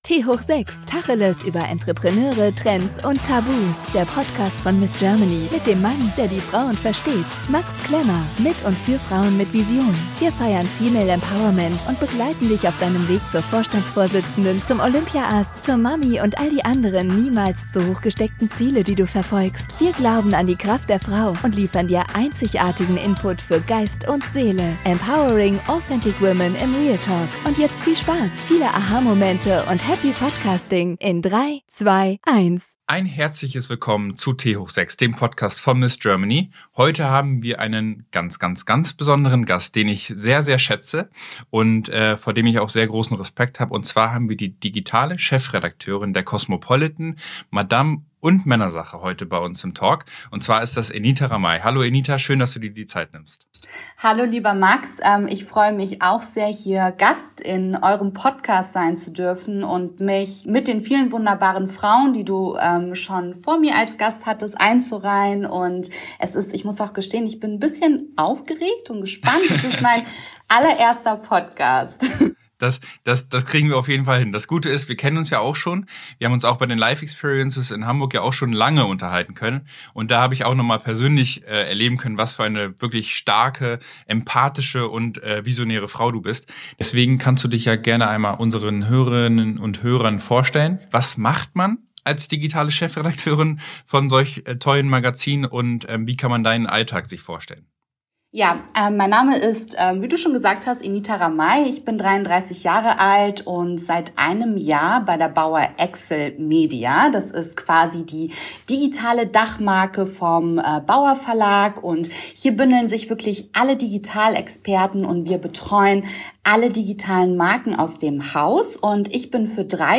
Wir sprechen im Tanga-Talk mit interessanten Frauen über Trends, aber genauso auch über Tabus.